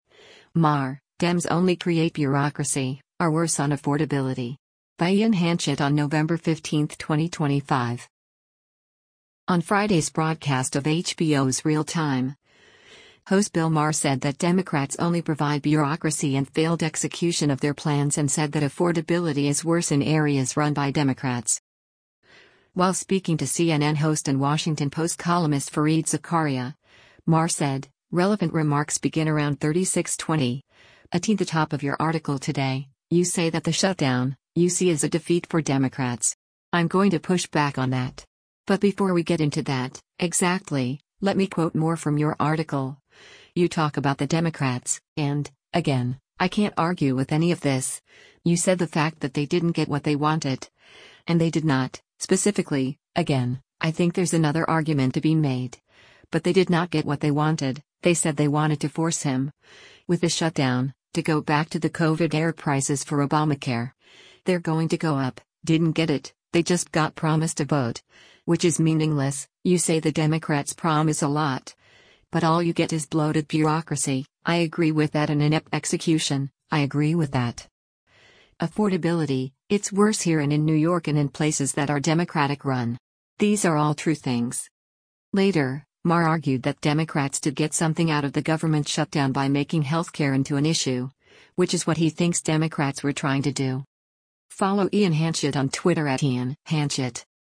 On Friday’s broadcast of HBO’s “Real Time,” host Bill Maher said that Democrats only provide bureaucracy and failed execution of their plans and said that affordability is worse in areas run by Democrats.